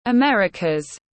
Americas /əˈmer·ɪ·kəz/